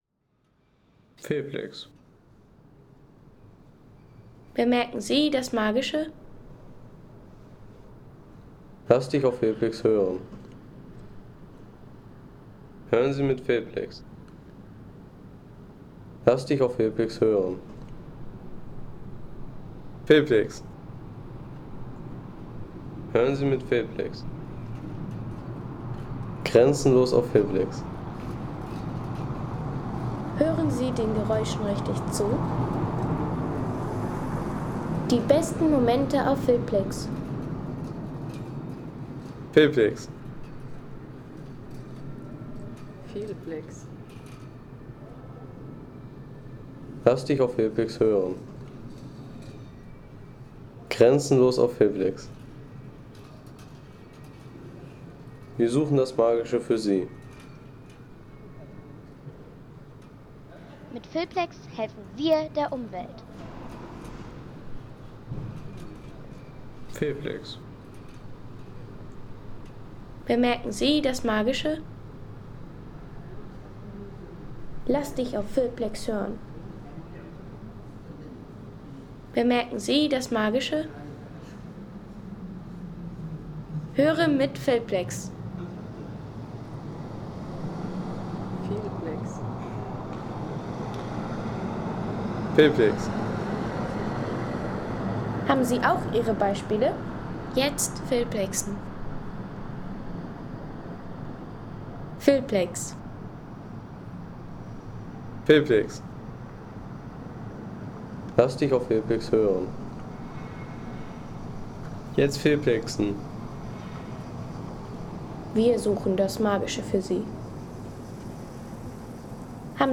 Innenstadt von Fulda
Abendliche Ruhe in der Innenstadt von Fulda.